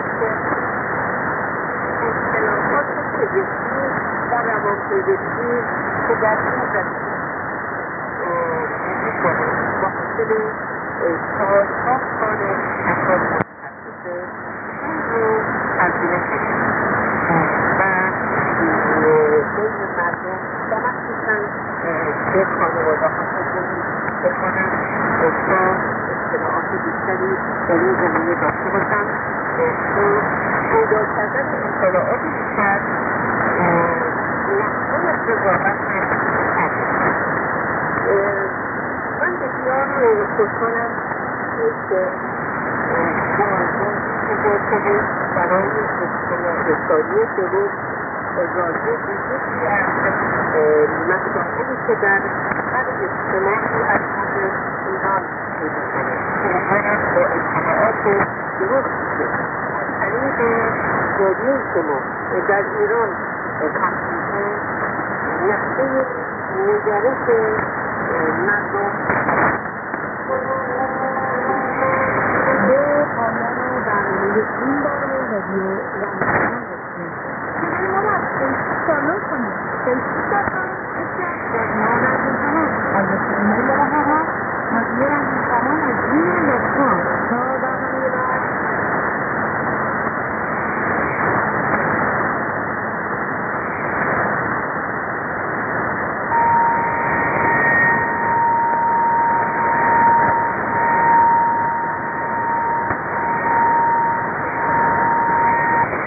IS: interval signal
ID: identification announcement